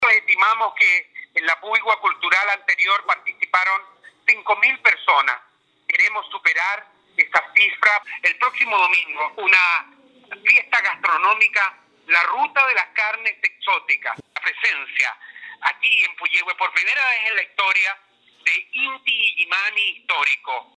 Al respecto, la máxima autoridad comunal destacó la trascendencia de un evento de esta índole, que potencia el turismo en la zona, recordando que la jornada de cierre, el día 19, estará marcada por la presentación de Intillimani Histórico y la Feria de Carnes Exóticas.